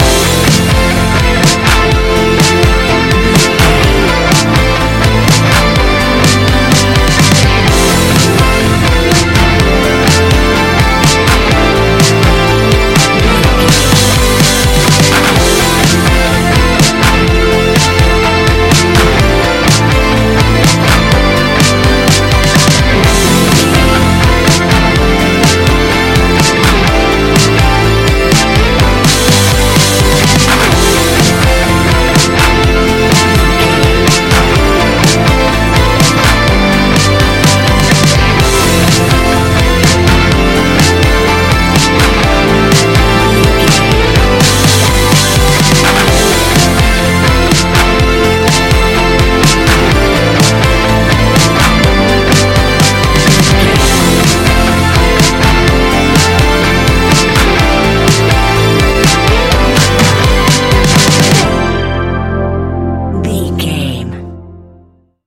Ionian/Major
ambient
electronic
new age
chill out
downtempo
synth
pads
drone